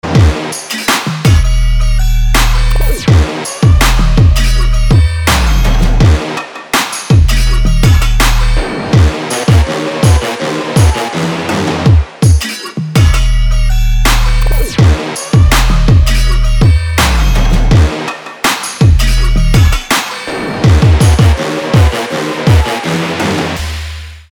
• Качество: 320, Stereo
громкие
dance
электронная музыка
без слов
Trap
колокола